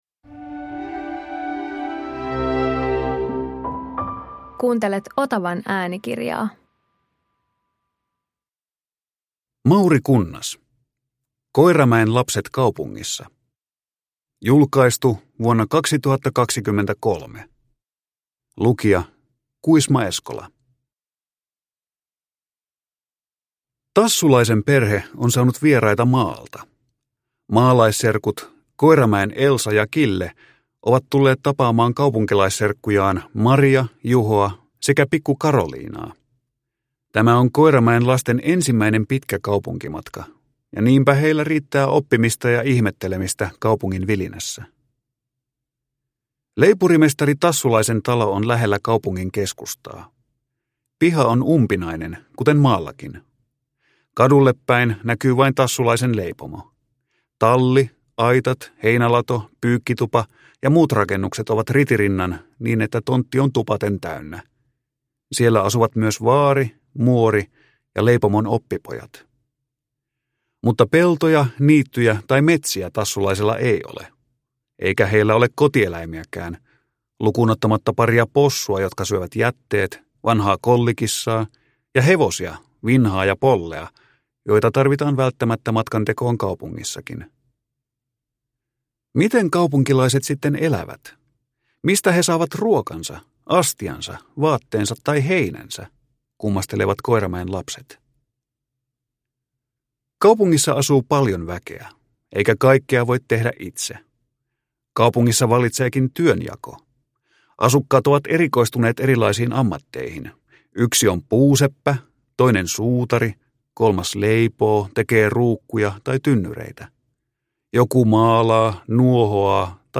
Koiramäen lapset kaupungissa – Ljudbok